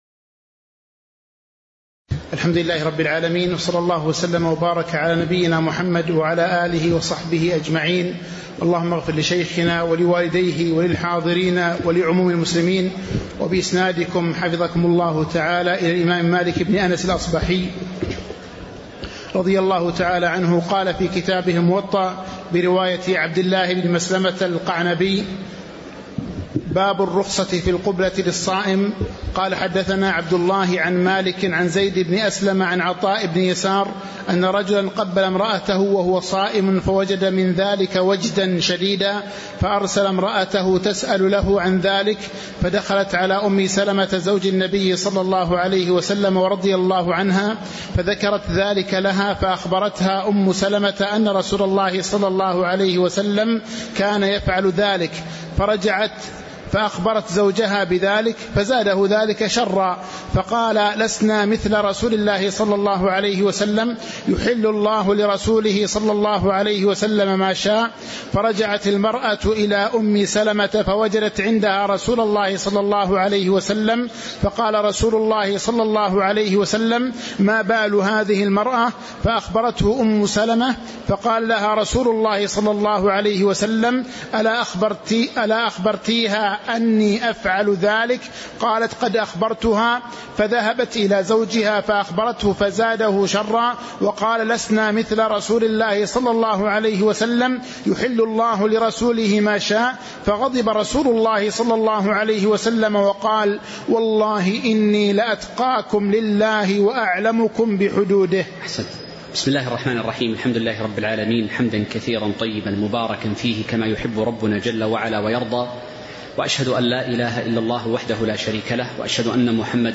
تاريخ النشر ٢٣ شعبان ١٤٤٦ هـ المكان: المسجد النبوي الشيخ